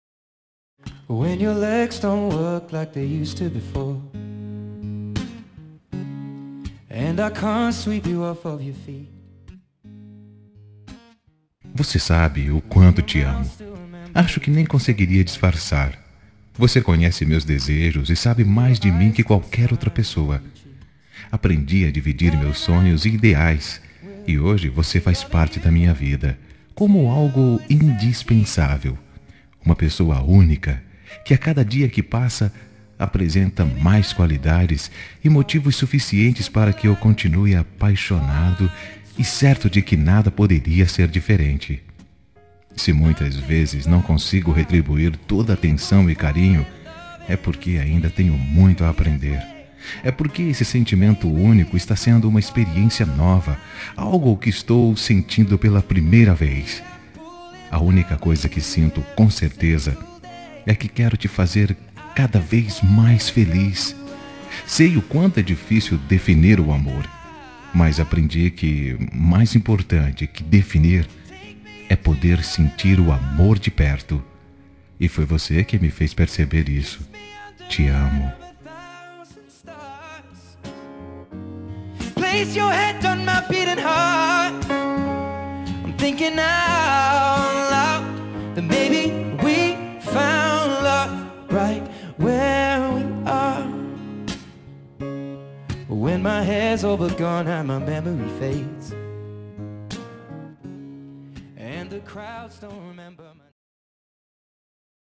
Telemensagem Início de Namoro – Voz Masculina – Cód: 753